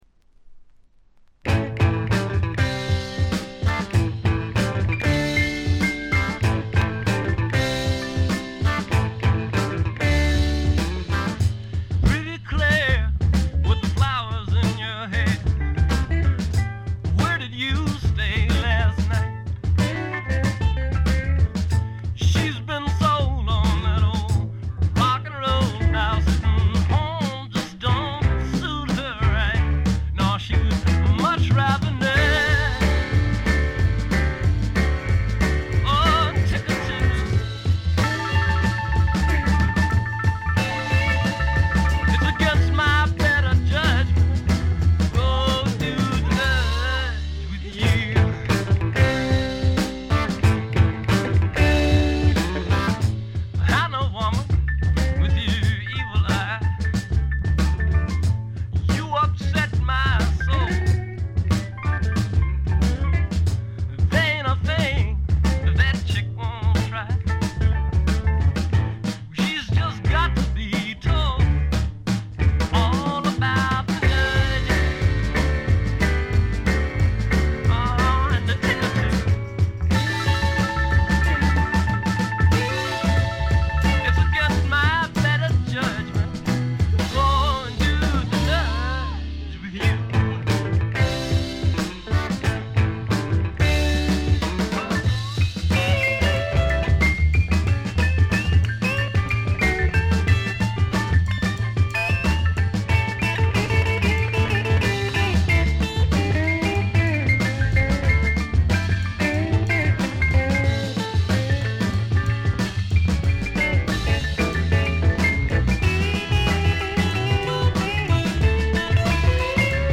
部分試聴ですが軽いチリプチ程度。
ロマンチシズムをたたえながらもメランコリックになり過ぎない、硬質な質感に貫かれたとても素敵なアルバム。
試聴曲は現品からの取り込み音源です。